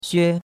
xue1.mp3